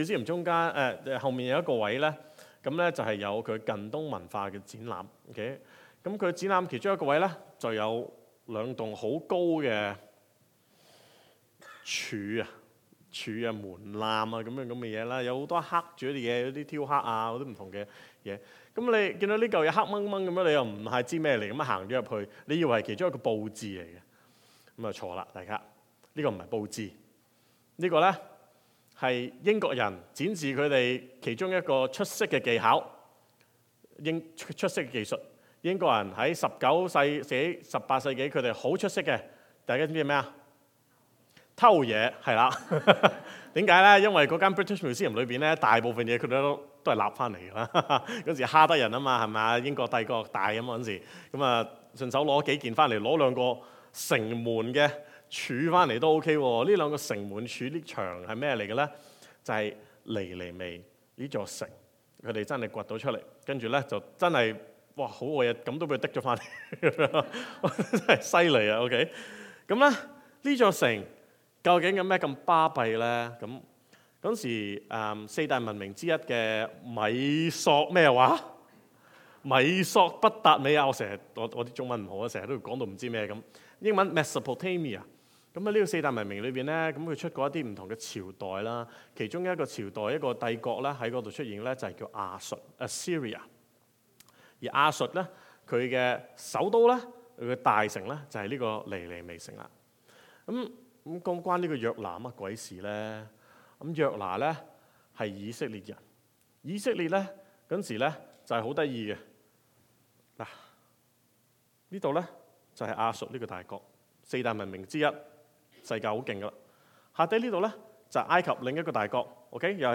先前粵語崇拜講道的錄音 | Burnaby Alliance Church